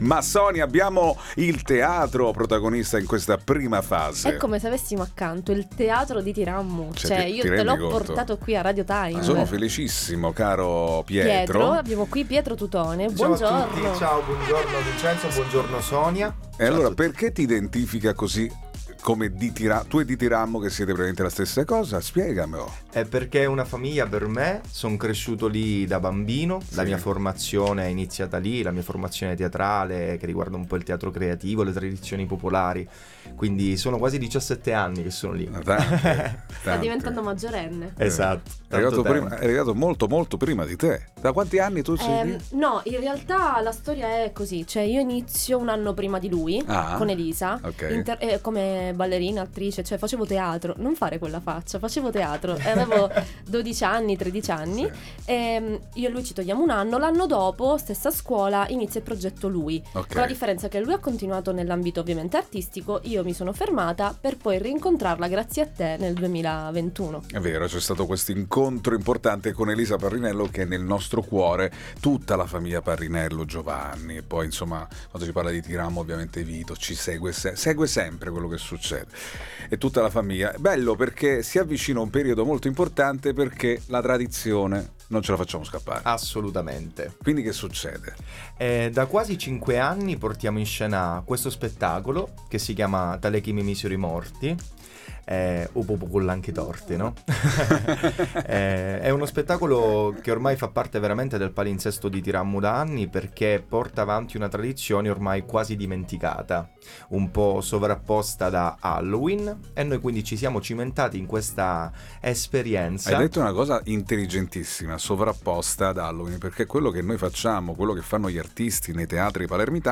All Inclusive Interviste